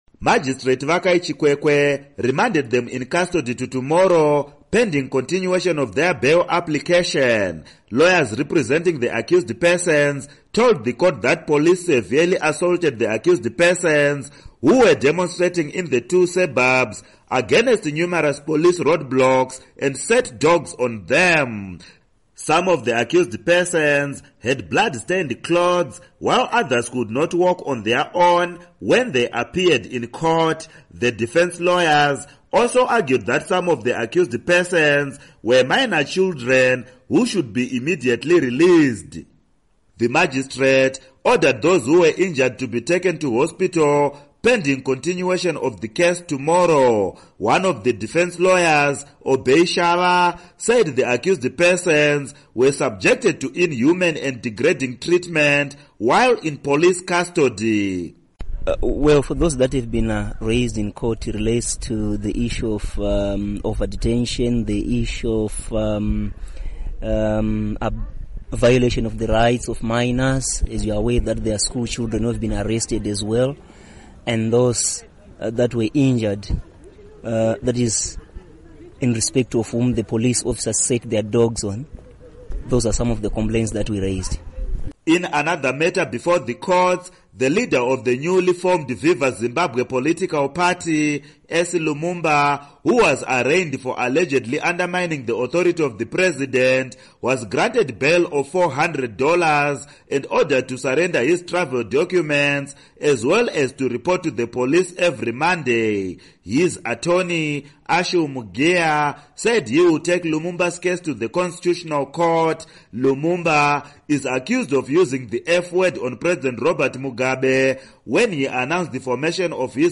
Report on Epworth